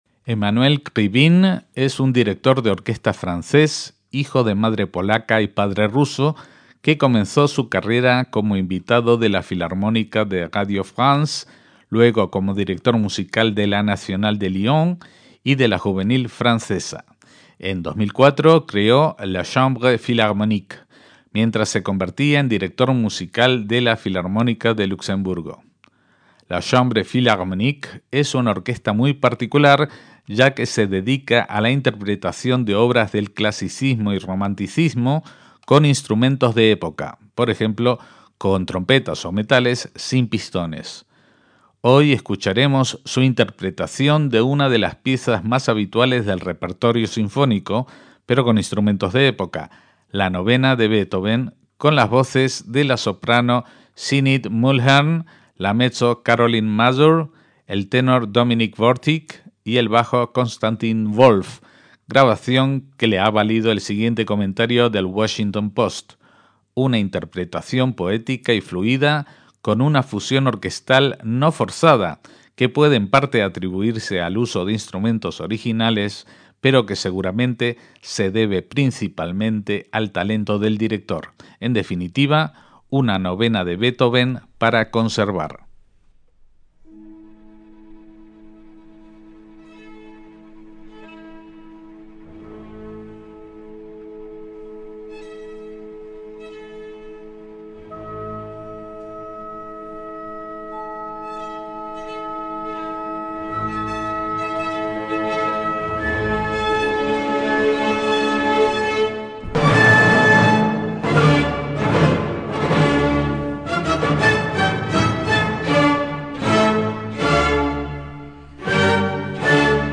Emmanuel Krivine dirige la Novena de Beethoven con instrumentos de época
MÚSICA CLÁSICA
con instrumentos de viento sin válvulas de pistón
El responsable del conjunto instrumental es el director de orquesta francés Emmanuel Krivine, nacido en Grenoble en 1947, hijo de madre polaca y padre ruso.